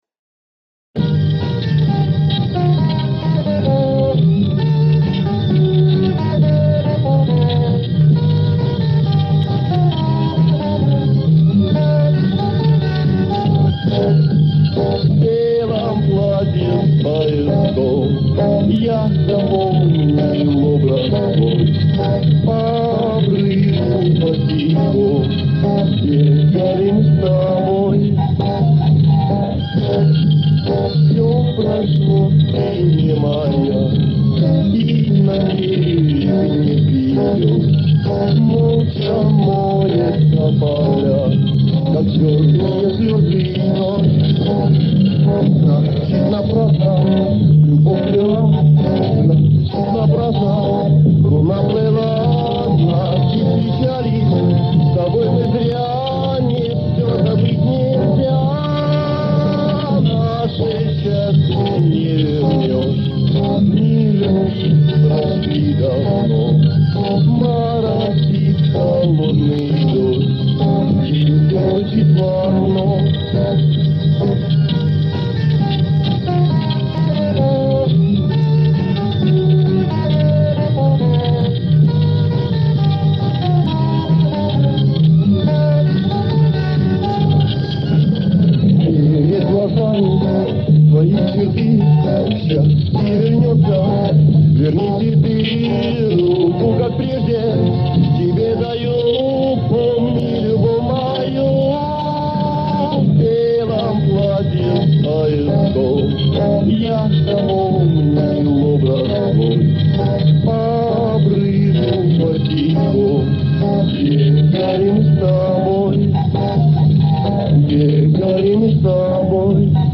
Привел в порядок, насколько это было можно. Убрал провалы.